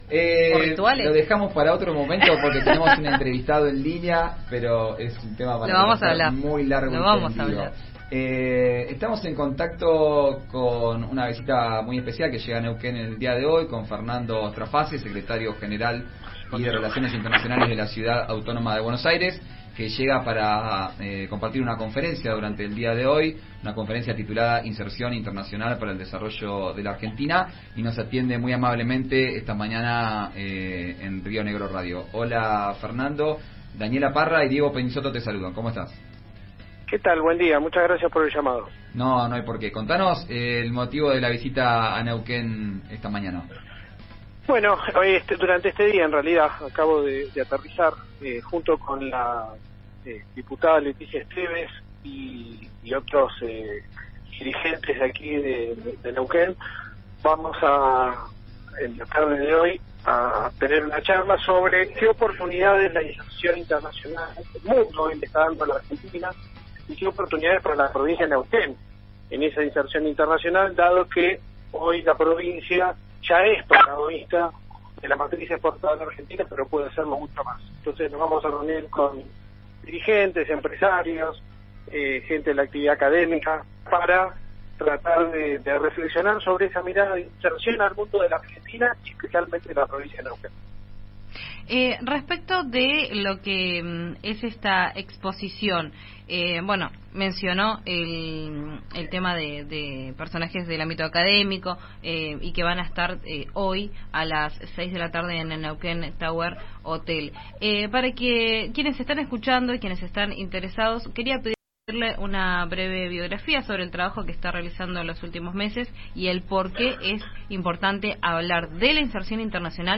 El secretario General y Relaciones Internacionales de la Ciudad de Buenos Aires, Fernando Straface, llegó a Neuquén para participar de un conversatorio y habló con 'Vos al Aire'. Escuchá la entrevista completa en RÍO NEGRO RADIO.